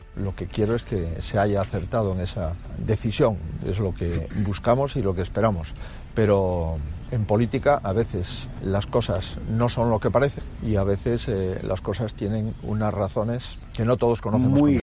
Palabras de Alberto Núñez Feijóo